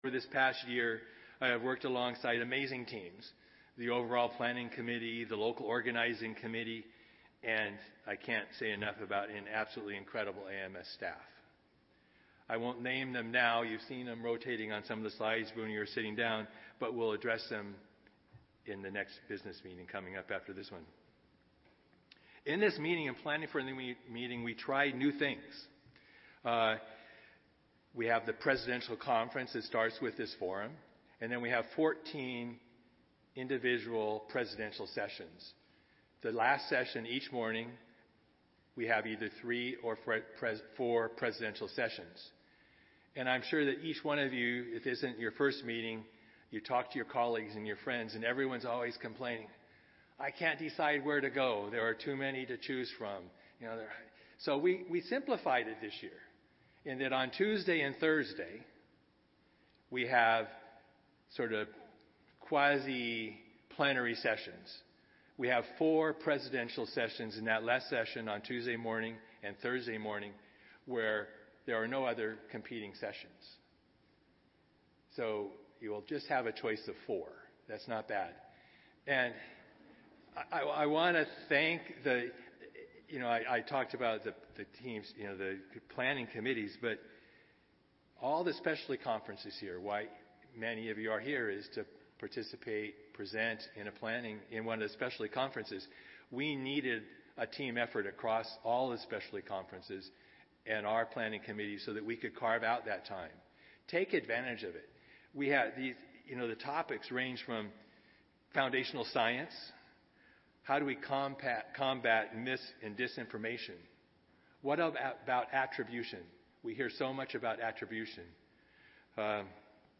Session: Transition to Carbon-Free Energy Generation (104th AMS Annual Meeting)
In this panel discussion, we aim to explore what some of these barriers are and how we can overcome existing constraints as we accelerate the transition toward carbon-free energy generation.